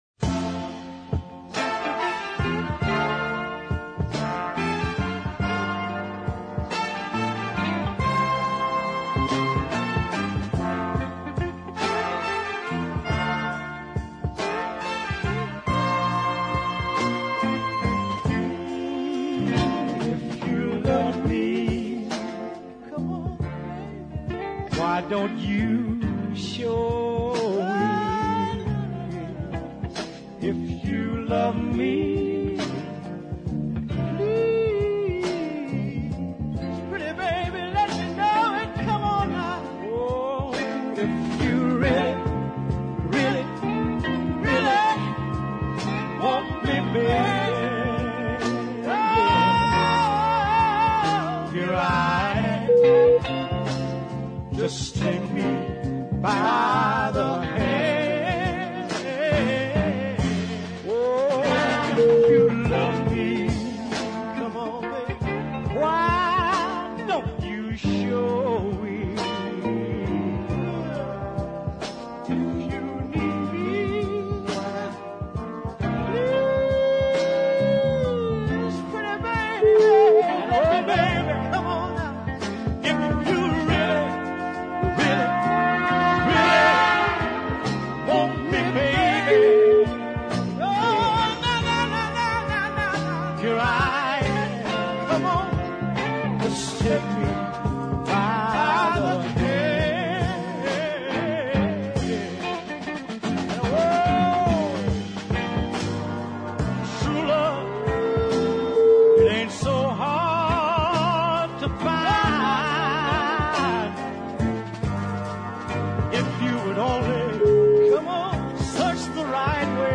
male duos
heartbreak deep soul ballad